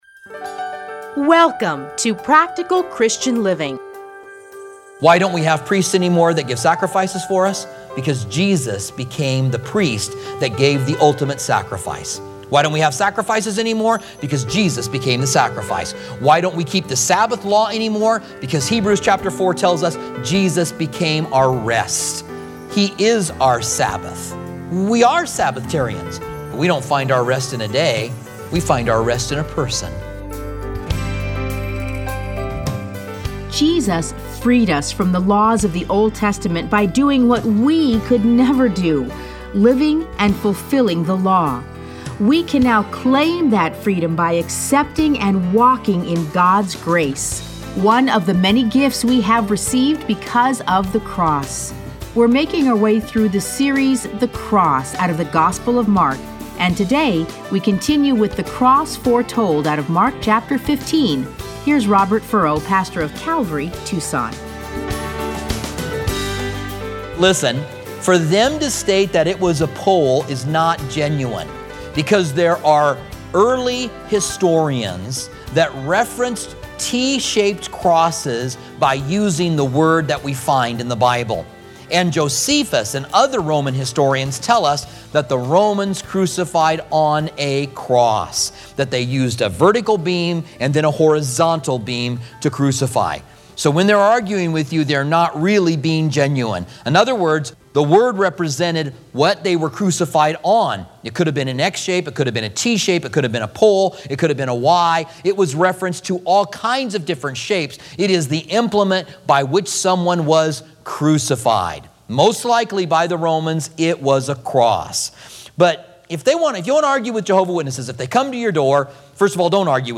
Listen to a teaching from Mark 15:20.